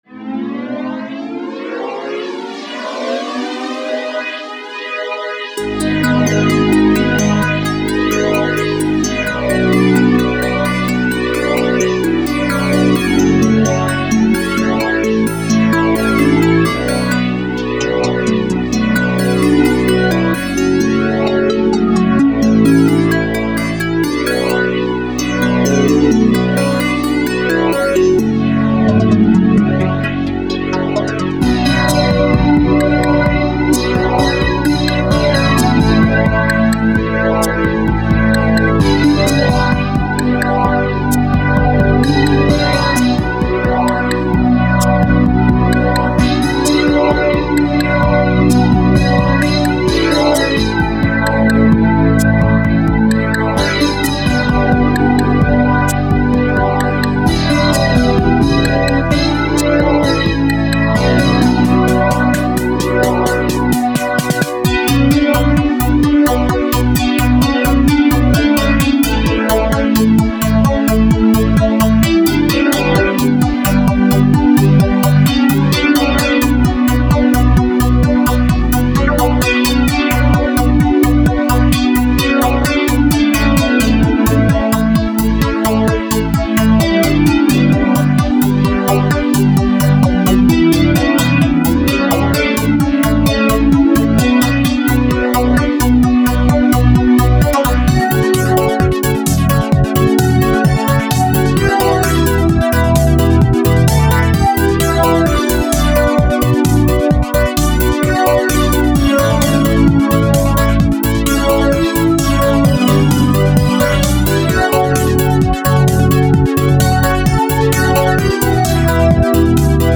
Жанр: Space, New Age.